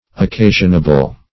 occasionable - definition of occasionable - synonyms, pronunciation, spelling from Free Dictionary